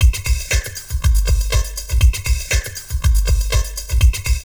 Index of /90_sSampleCDs/USB Soundscan vol.07 - Drum Loops Crazy Processed [AKAI] 1CD/Partition B/07-120FLUID